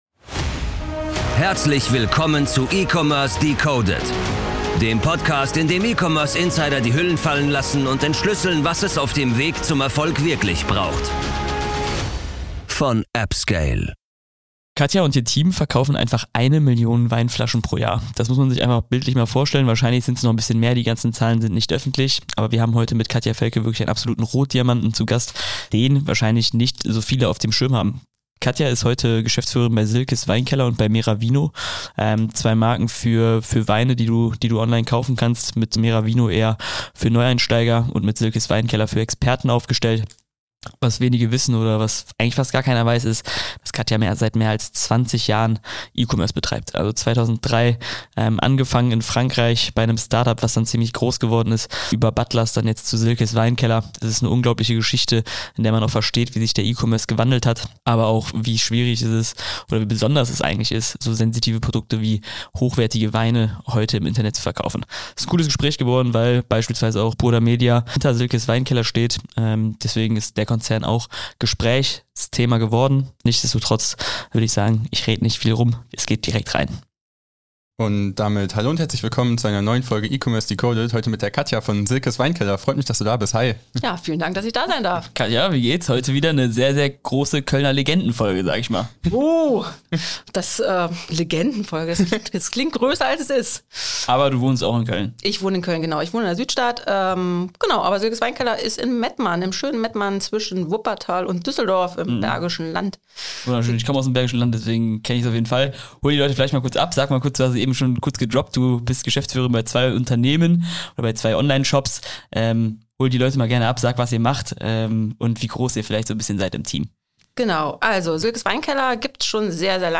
Es ist ein beeindruckendes Gespräch darüber geworden, wie sich der E-Commerce seit 2000 verändert hat, aber auch wie man sensitive Produke, wie einen Wein, digital spürbar macht und eben auch verkauft.